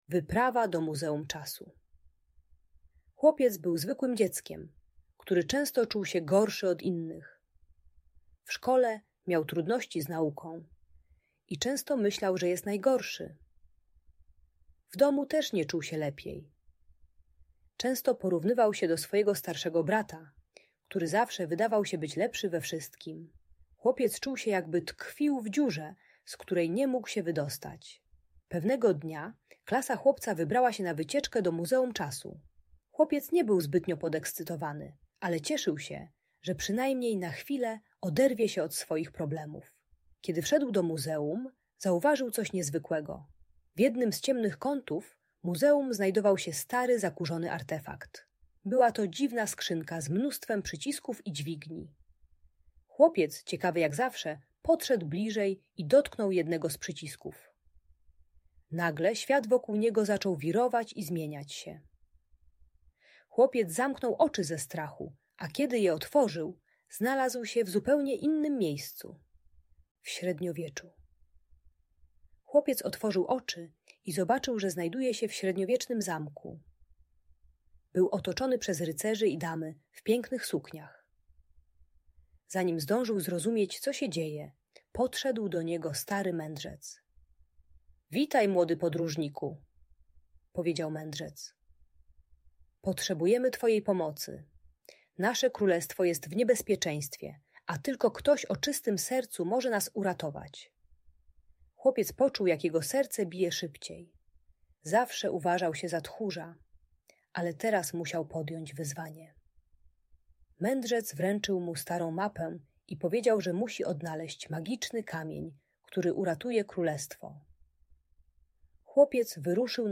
Wyprawa do Muzeum Czasu - Audiobajka dla dzieci